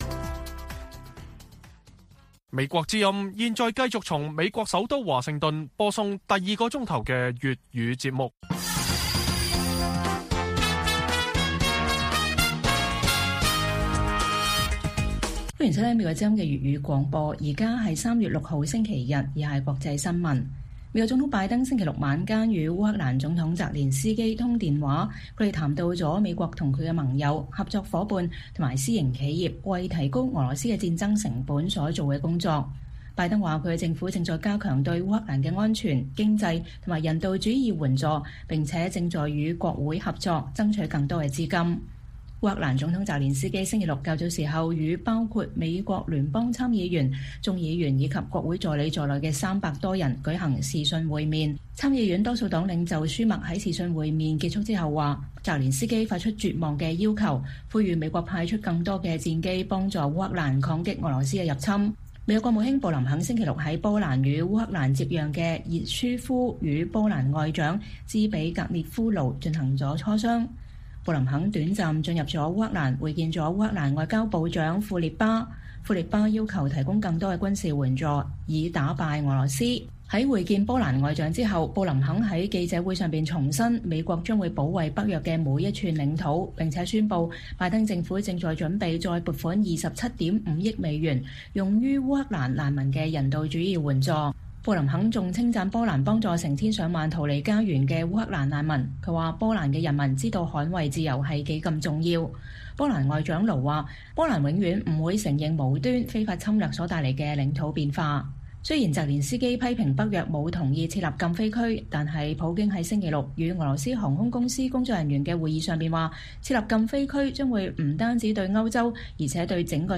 粵語新聞 晚上10-11點: 拜登和澤連斯基討論提高俄羅斯的戰爭成本